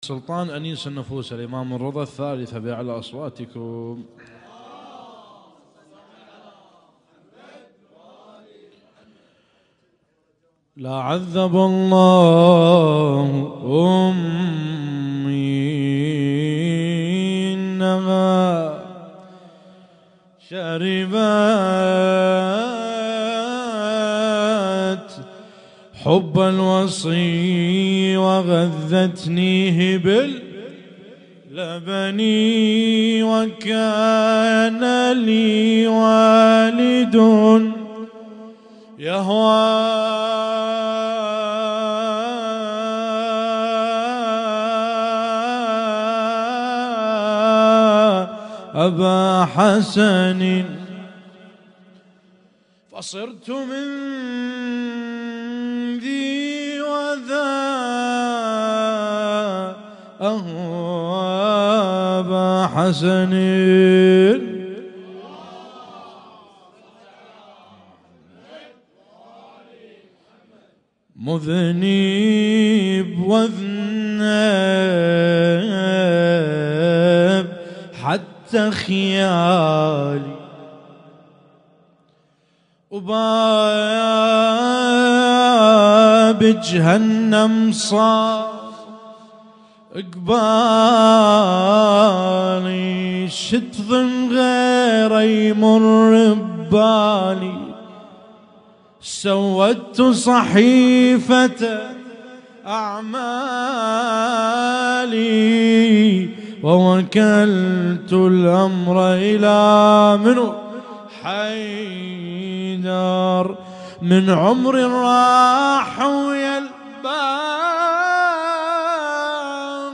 Husainyt Alnoor Rumaithiya Kuwait
القارئ: - الرادود